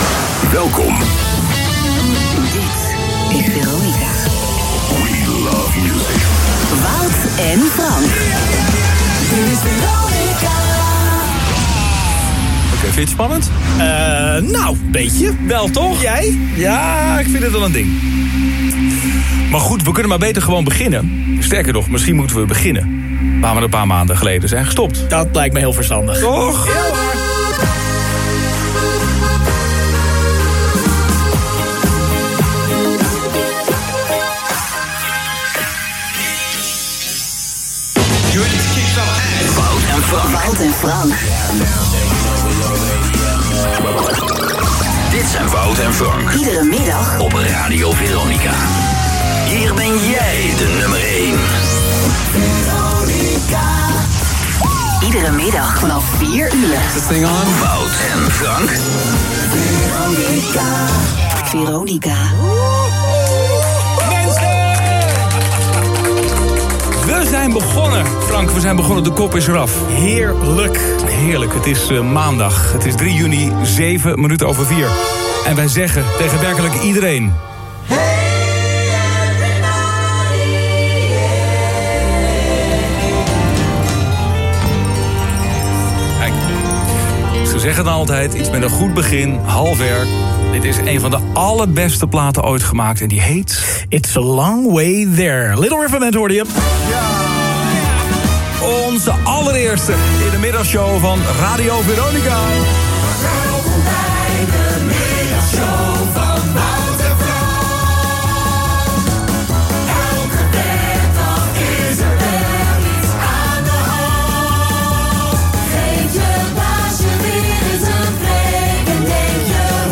We kunnen maar beter gewoon beginnen. Sterker nog, misschien moeten we beginnen waar we een paar maanden geleden zijn gestopt”, met deze woorden opende Wouter van der Goes de nieuwe middagshow op Radio Veronica, waarna hij ‘Engelbewaarder’ instartte.